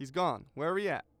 Combat Dialogue